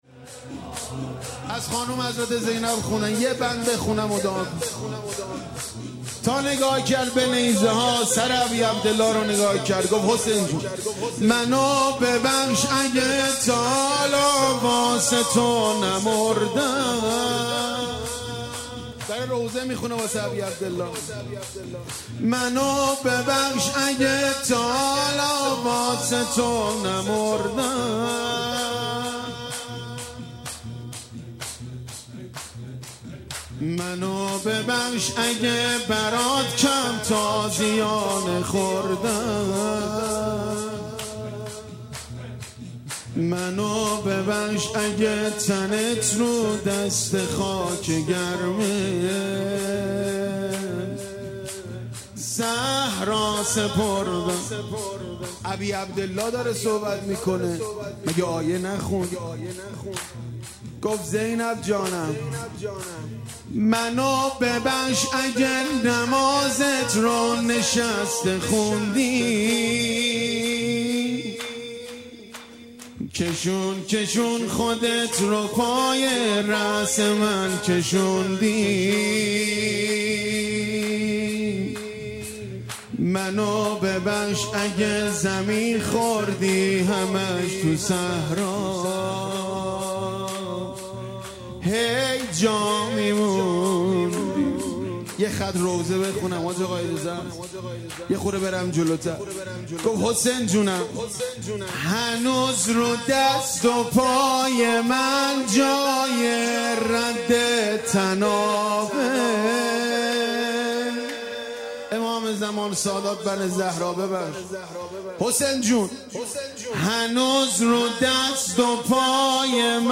روضةالشهداء
شب دوازدهم ماه رمضان
شور مداحی